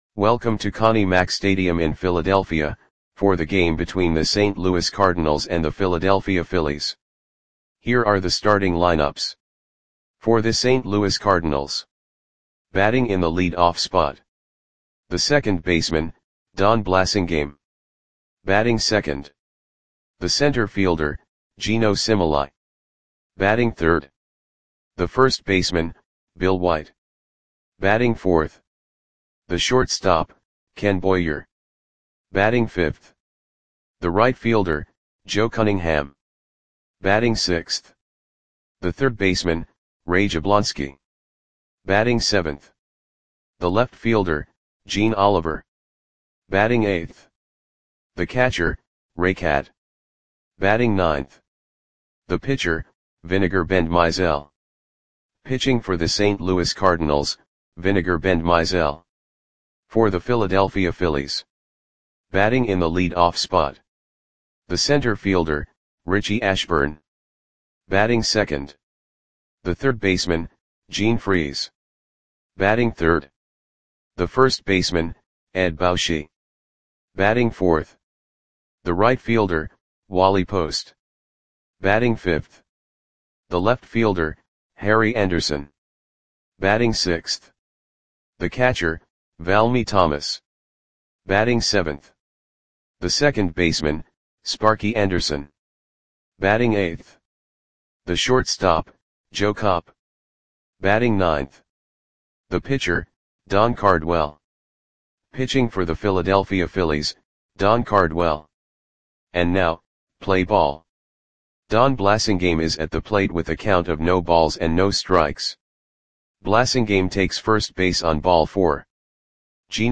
Audio Play-by-Play for Philadelphia Phillies on June 7, 1959
Click the button below to listen to the audio play-by-play.